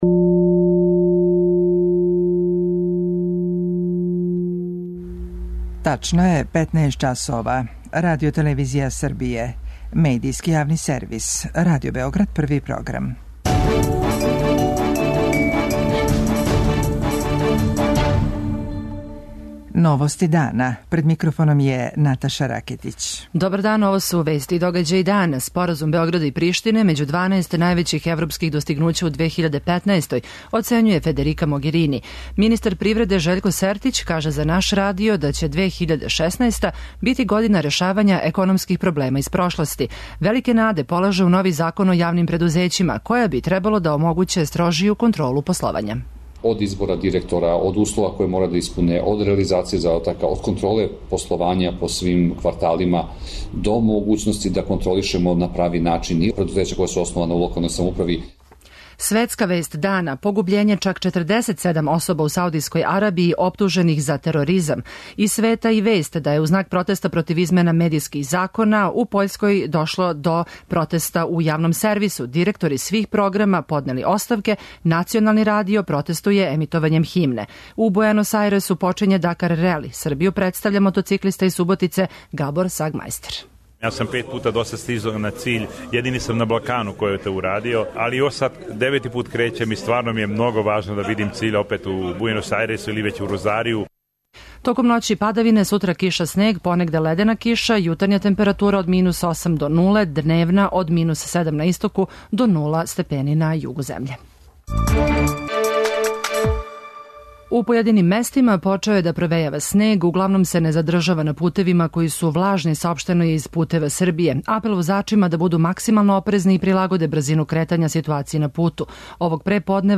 Министар привреде Жељко Сертић изјавио је за наш програм да ће 2016. бити година чишћења домаће економије од терета из прошлости, било да се ради о јавним и државним предузећима која послују неефикасно, било да је реч о завршетку реструктурирања у 17 преосталих великих система. У новогодишњем интервјуу за Радио Београд 1 Сертић наглашава да ће предузетници убудуће моћи да рачунају на бољу заштиту од злоупотреба, а подсећа и да је у току јавна расправа о новом Закону који би требало да омогући строжију контролу пословања али и постављања директора у јавним предузећима.